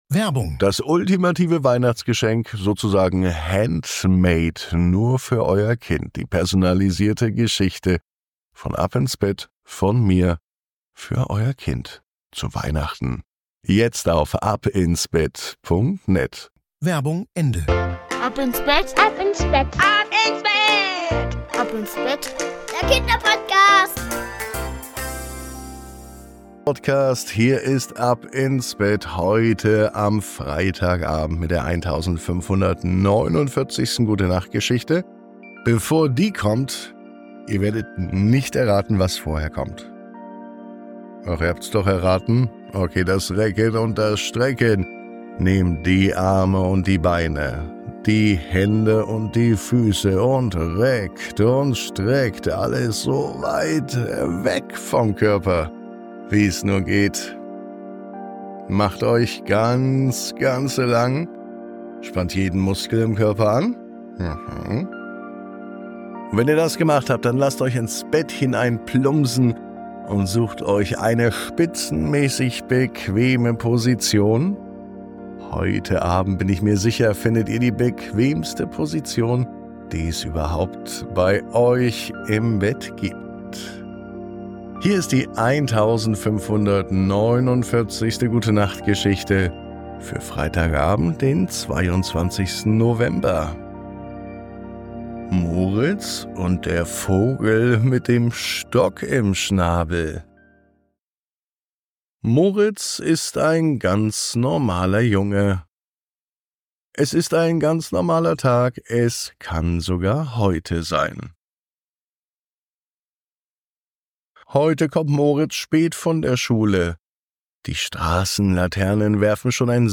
Die Gute Nacht Geschichte für Freitag